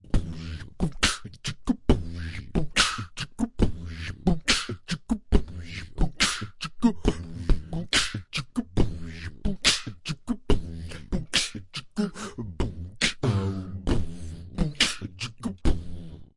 人声样本 " BBOXK
Tag: 声乐 语音 α-清唱 口语